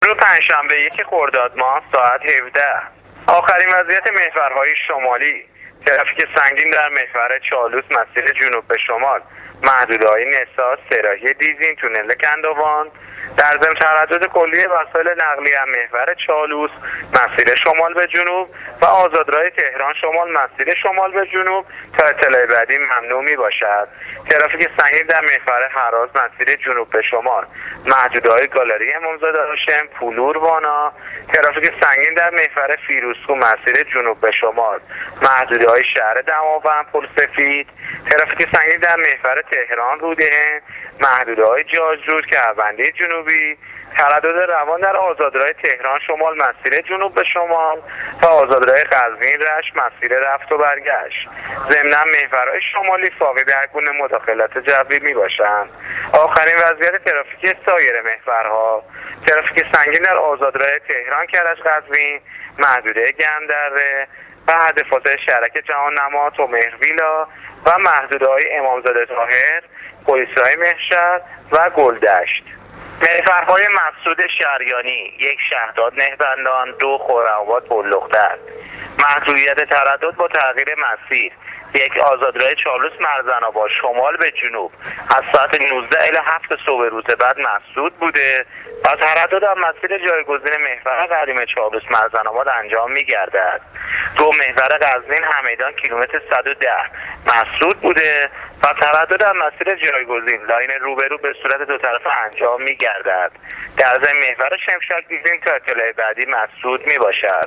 گزارش رادیو اینترنتی از وضعیت ترافیکی جاده‌ها تا ساعت ۱۷ یکم خردادماه